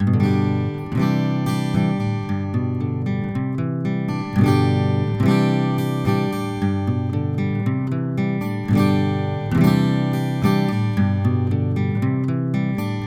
Pour les prises de son, j’ai utilisé un préamplificateur Neve 4081 quatre canaux avec la carte optionnelle Digital l/O qui convertit l’analogique en numérique AES sur une SubD25 ou en Firewire.
Les échantillons n’ont subi aucun traitement.
Enregistrements d’une guitare acoustique (AIFF) :
AKG414ULS_Prise4.aif